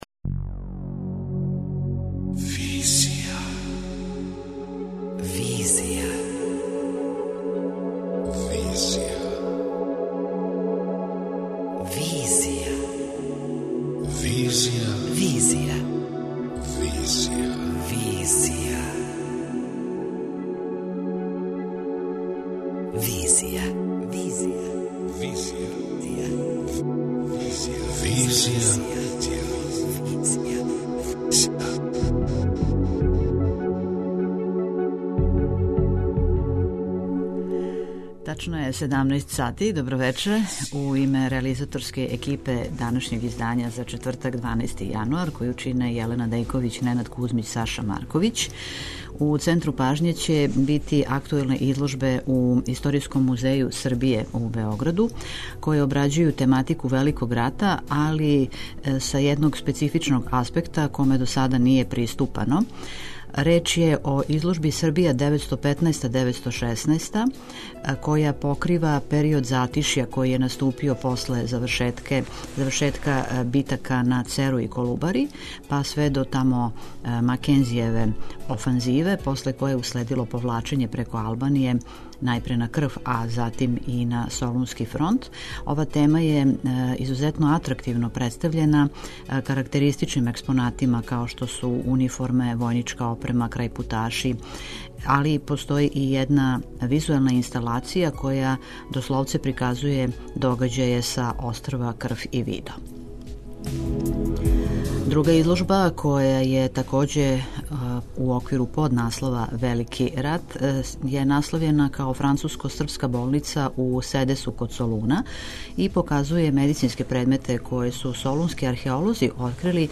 преузми : 28.13 MB Визија Autor: Београд 202 Социо-културолошки магазин, који прати савремене друштвене феномене.